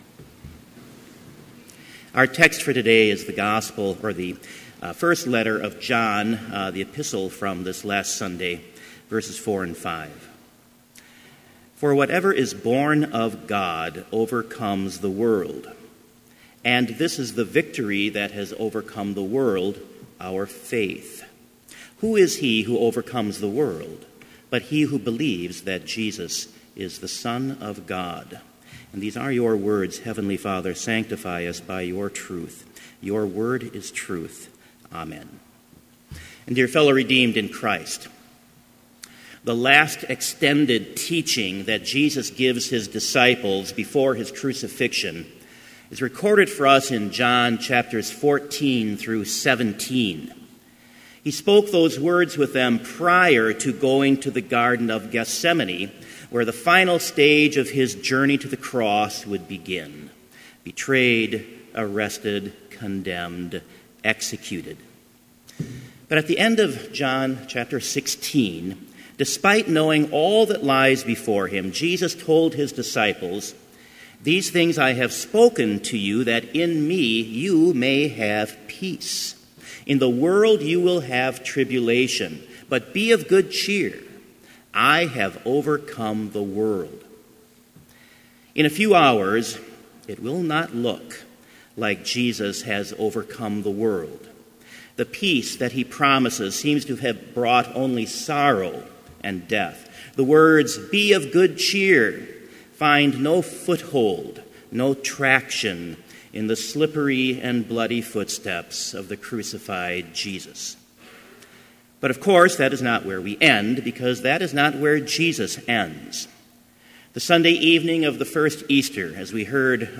Complete Service
Order of Service
This Chapel Service was held in Trinity Chapel at Bethany Lutheran College on Thursday, April 27, 2017, at 10 a.m. Page and hymn numbers are from the Evangelical Lutheran Hymnary.